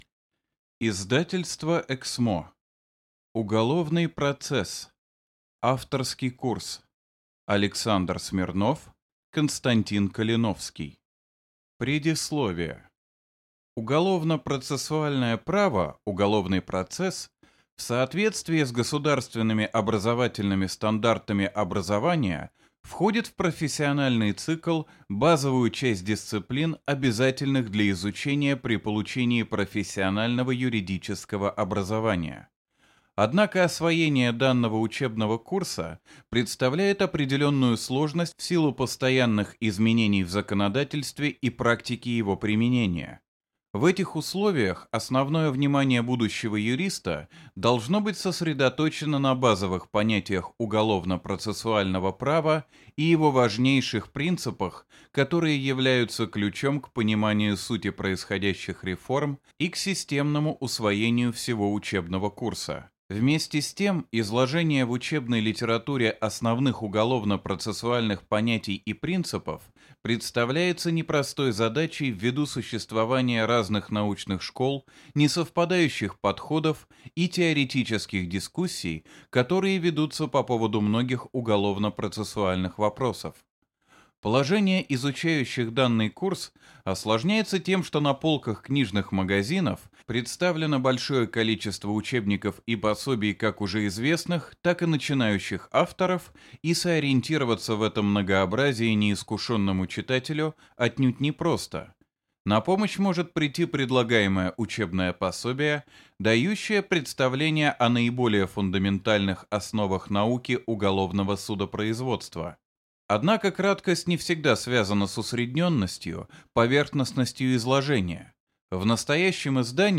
Аудиокнига Уголовный процесс. Авторский курс | Библиотека аудиокниг